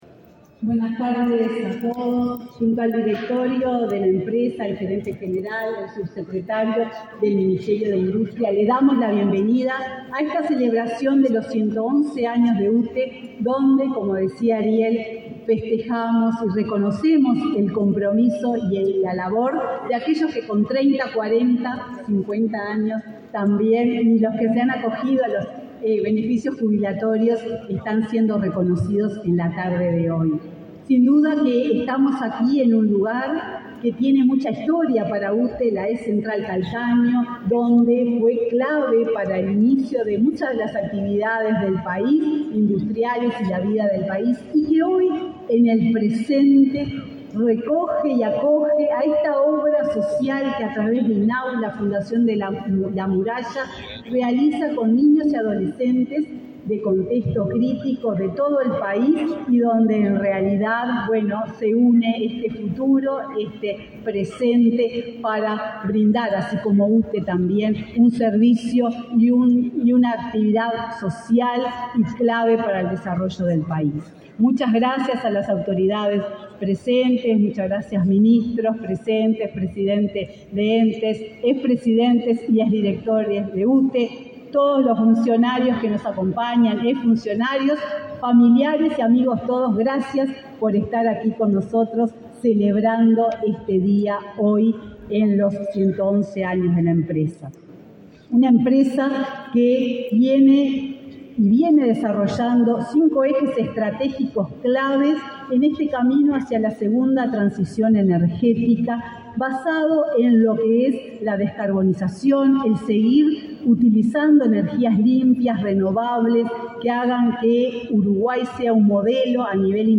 Palabras de la presidenta de UTE, Silvia Emaldi, y del subsecretario de Industria, Walter Verri
Palabras de la presidenta de UTE, Silvia Emaldi, y del subsecretario de Industria, Walter Verri 24/10/2023 Compartir Facebook X Copiar enlace WhatsApp LinkedIn Este 24 de octubre la UTE festejó su 111.° aniversario. En el evento disertó su presidenta Silvia Emaldi, y el subsecretario de Industria, Walter Verri.